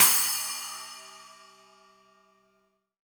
• Long Cymbal Sound Sample E Key 02.wav
Royality free cymbal tuned to the E note. Loudest frequency: 7827Hz
long-cymbal-sound-sample-e-key-02-5TJ.wav